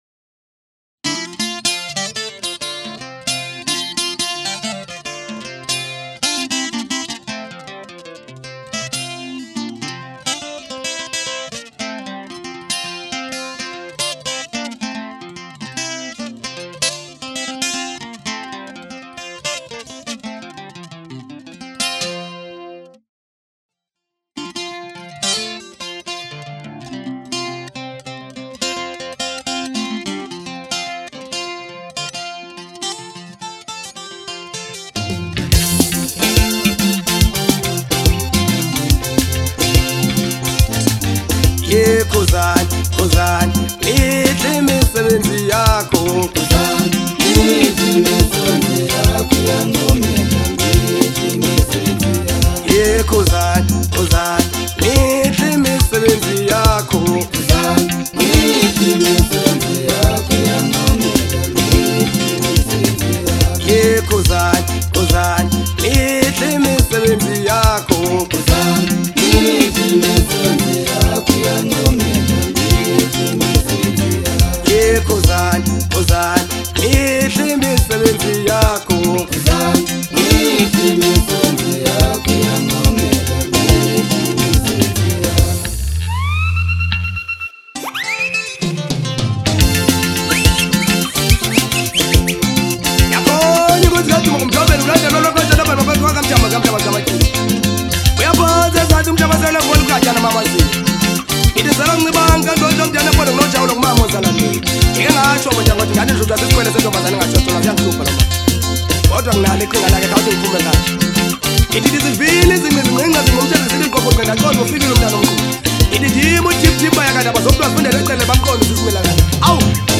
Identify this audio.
Genre : Maskandi